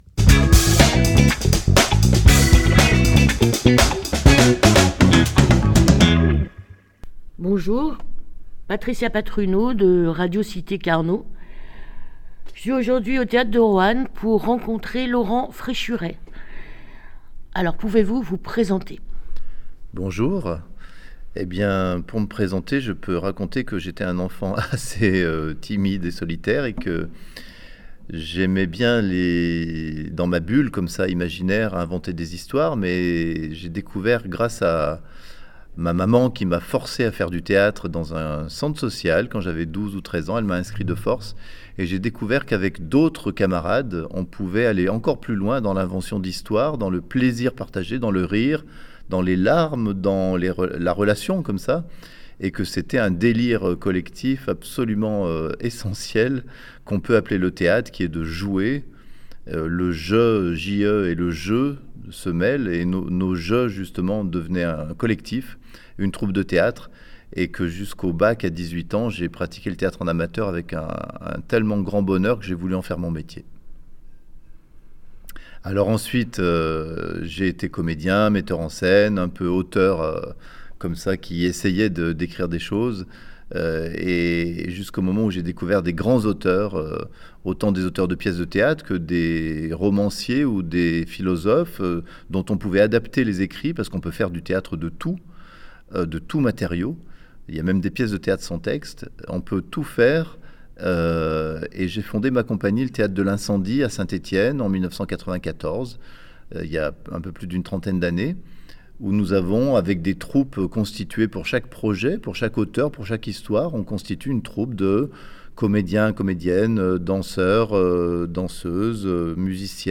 sur la scène du beau théâtre de Roanne.
Voici l'interview :